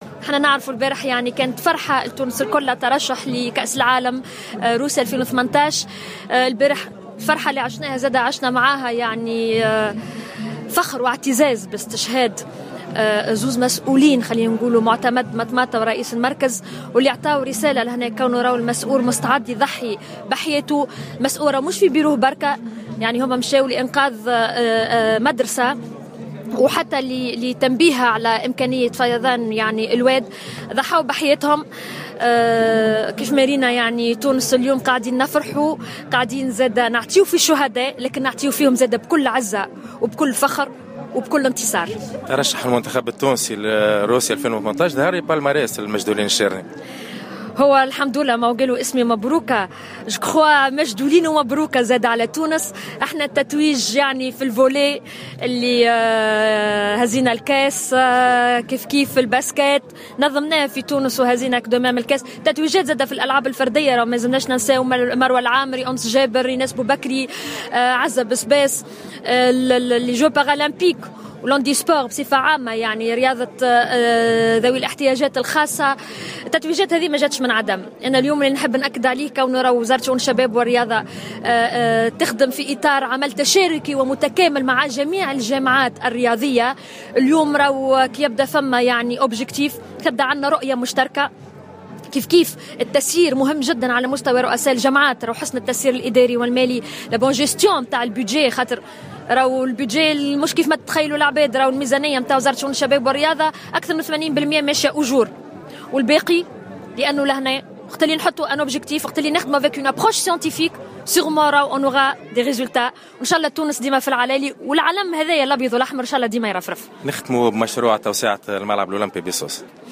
تحدثت وزيرة شؤون الشباب والرياضة ماجدولين الشارني في تصريح خاص لجوهرة أف أم على هامش زيارتها لمدينة سوسة للإحتفال بالعيد الوطني للشجرة عن ترشح المنتخب الوطني لكرة القدم إلى كأس العالم روسيا 2018 بالإضافة إلى اخر المستجدات حول تقدم تنفيذ مشروع توسعة الملعب الأولمبي بسوسة .